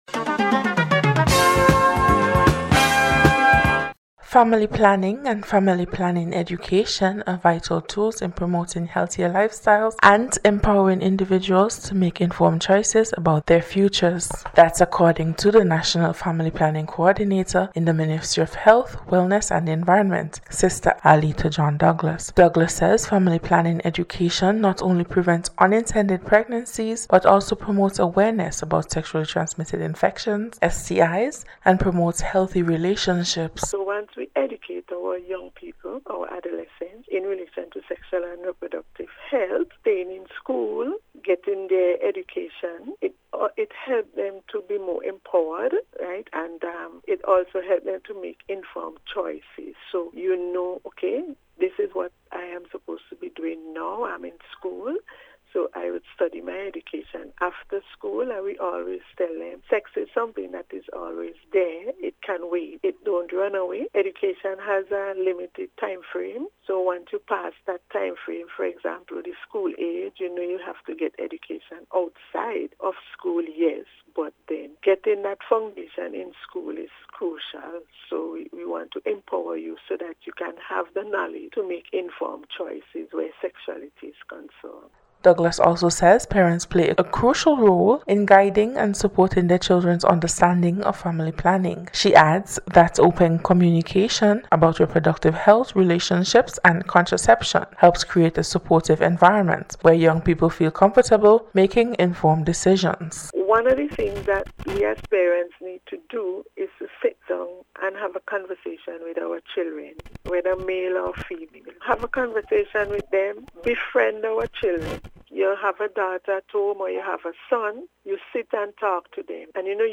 NBC’s Special Report- Tuesday 21st January,2025